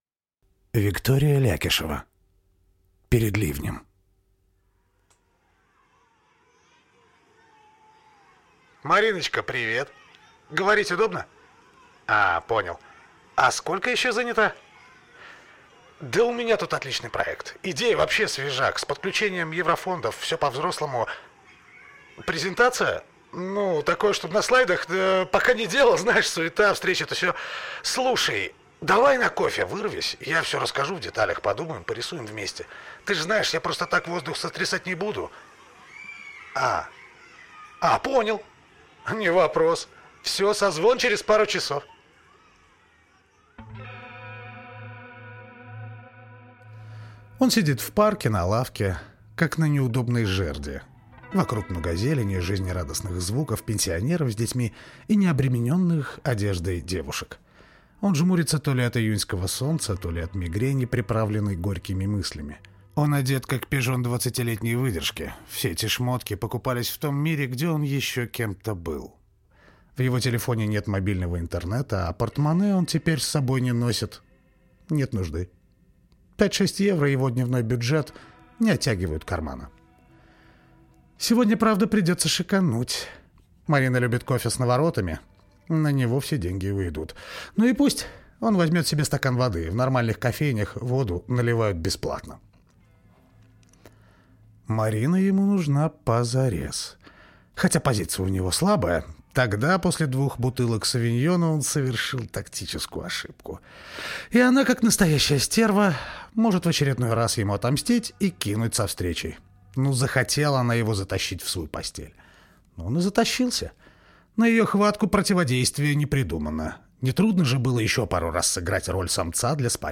Специально для них мы подготовили маленький аудиоспектакль.